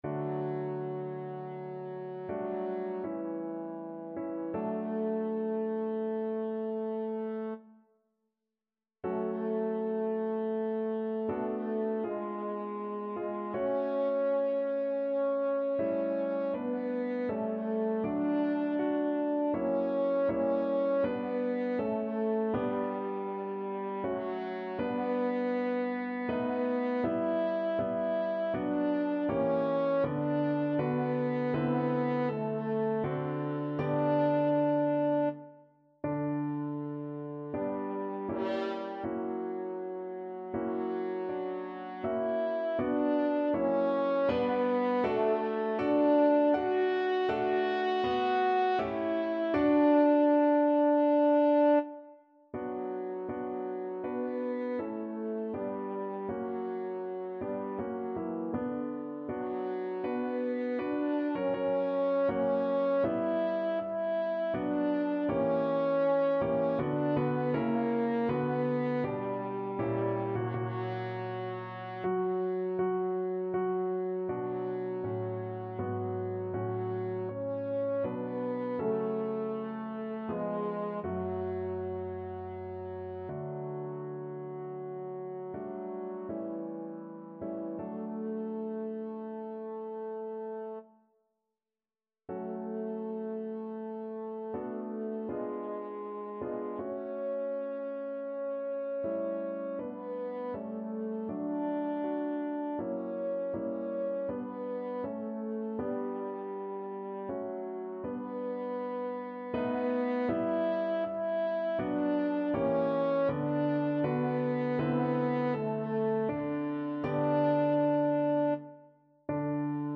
3/4 (View more 3/4 Music)
~ = 80 Andante ma non lento
Classical (View more Classical French Horn Music)